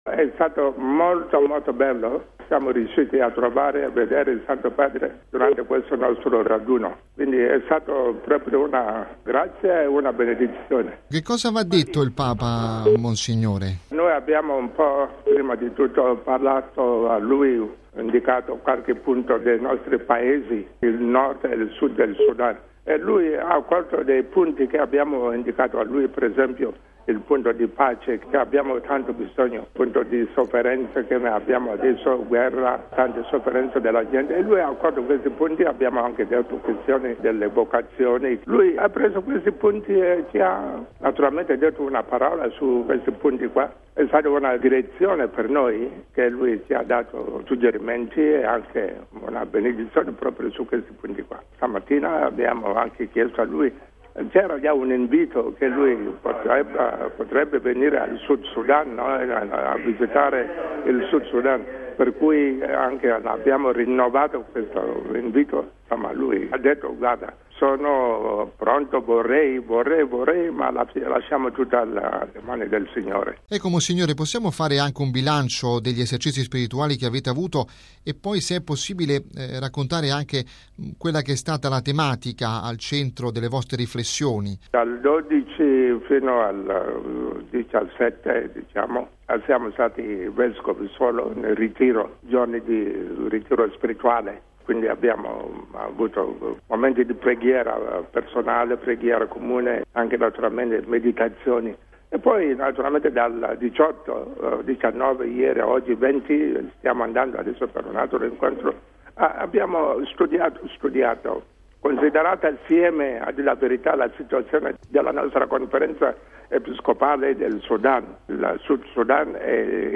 Sul colloquio con Papa Francesco, ascoltiamo l’arcivescovo di Juba Paulino Lukudu Loro